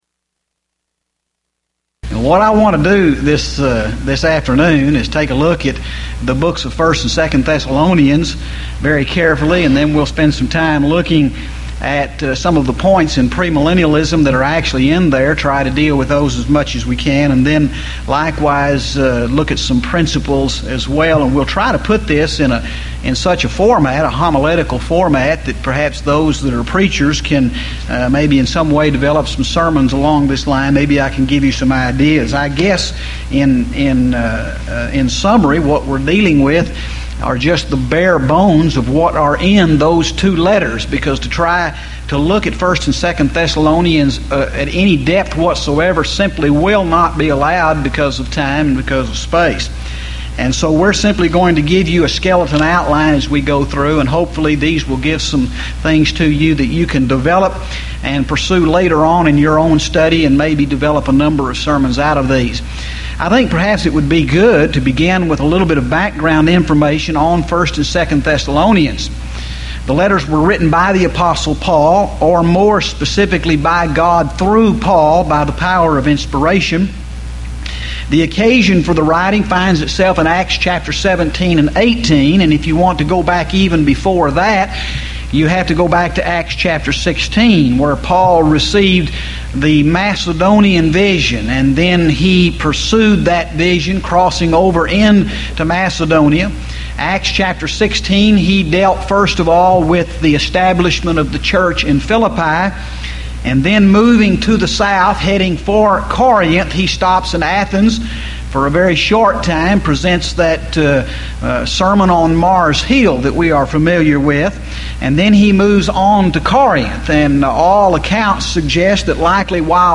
Series: Houston College of the Bible Lectures Event: 1997 HCB Lectures Theme/Title: Premillennialism